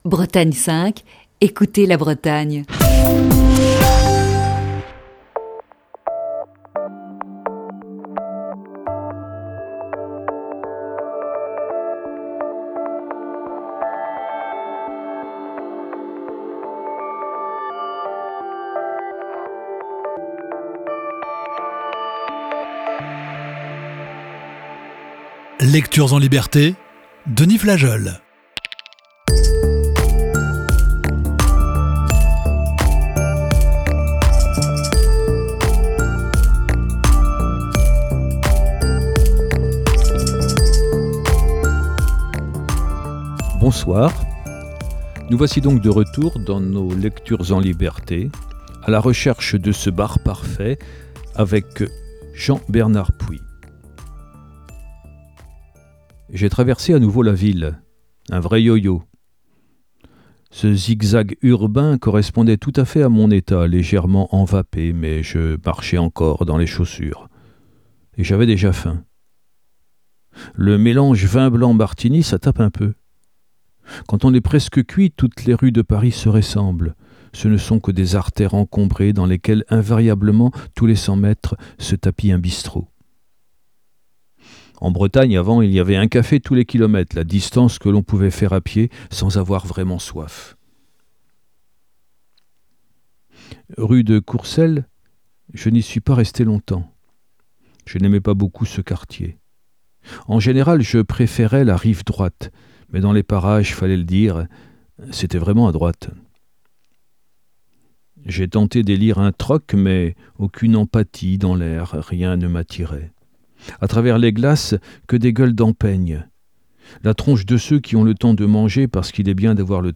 Émission du 12 juin 2020.